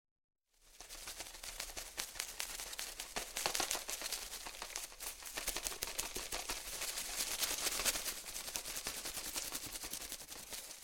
Звуки мотылька
На этой странице собраны уникальные звуки мотыльков: от легкого шелеста крыльев до едва уловимого трепета в ночи.
Шепот мотылька в стеклянной банке